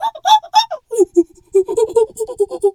monkey_2_chatter_14.wav